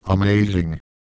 Worms speechbanks
amazing.wav